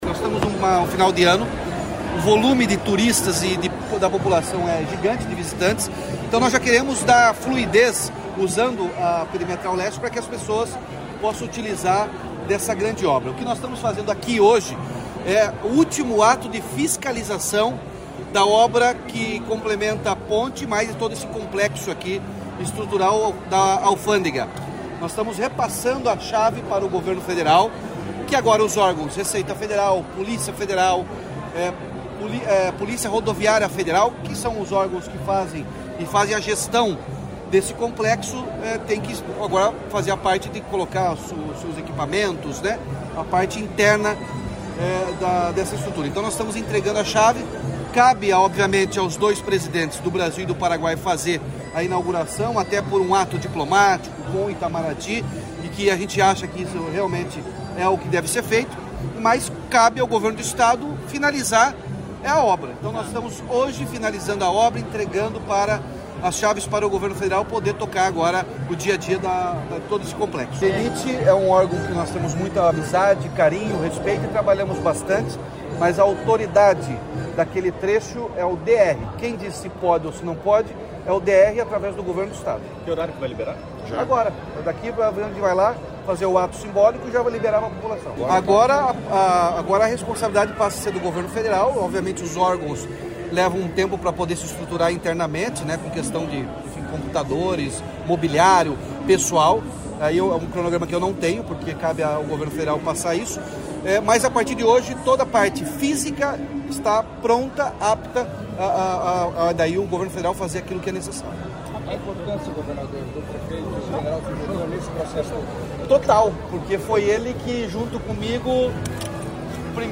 Sonora do governador Ratinho Junior sobre a abertura da Perimetral Leste, em Foz